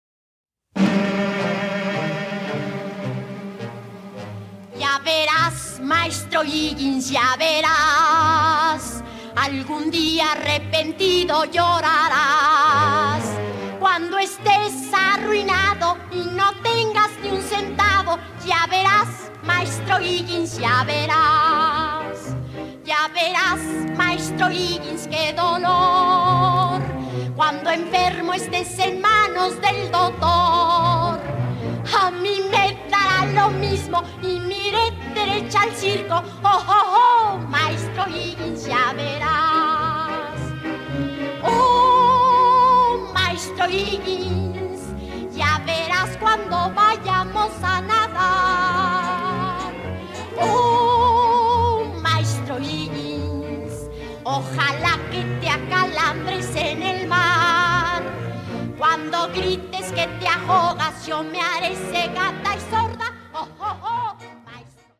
Mexican Cast Recording